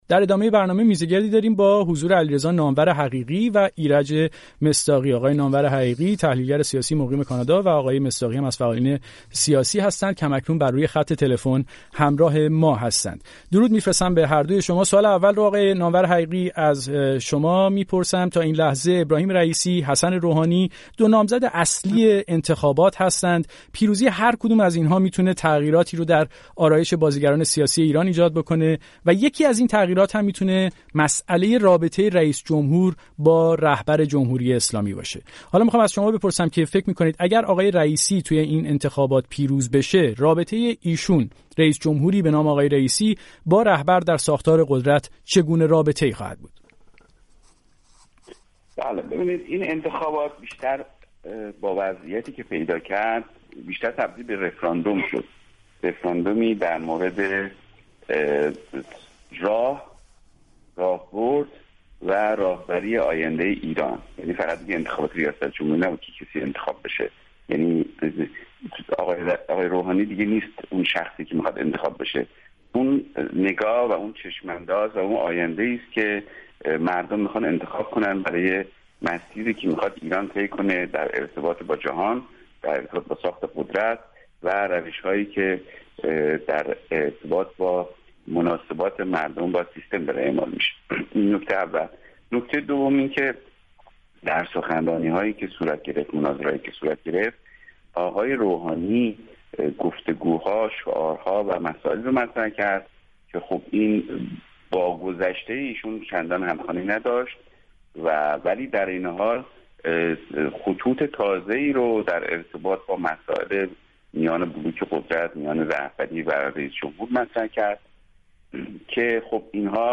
میزگردی